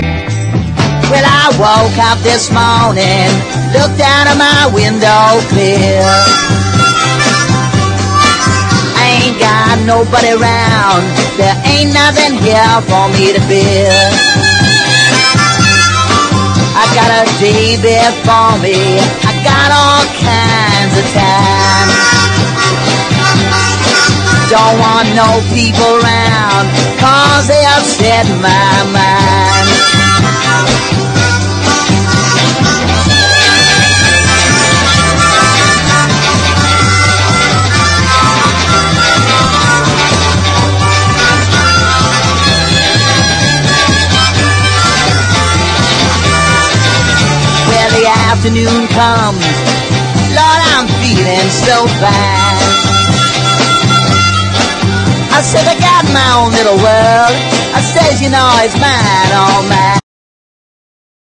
BLUE GRASS / COUNTRY / FOLK
コンテンポラリーな響きを持ったブルーグラス・デュオの傑作！